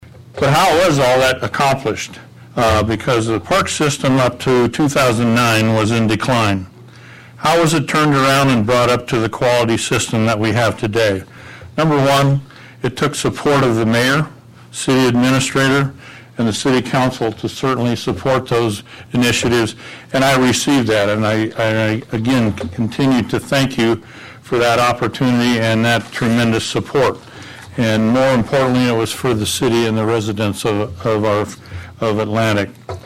(Atlantic) A public hearing was held at the Atlantic City Council meeting Wednesday evening on a proposal to discontinue the Atlantic Parks and Recreation Board.